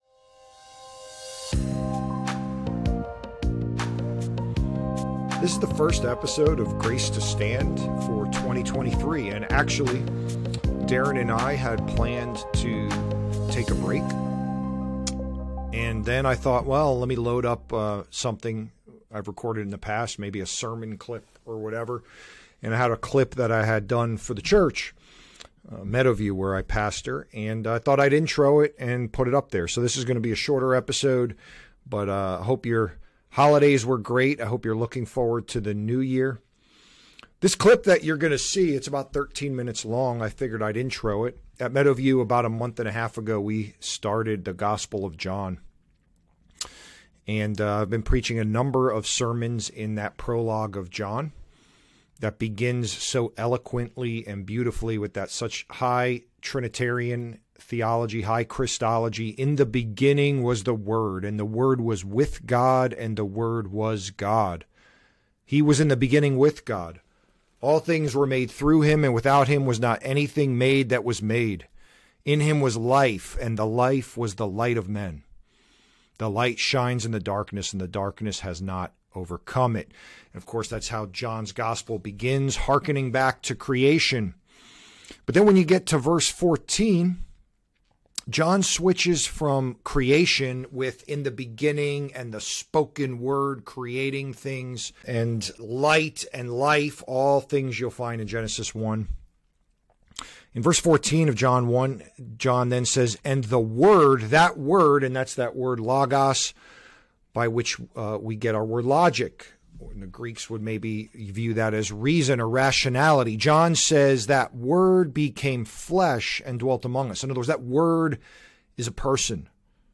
sermon-clip.mp3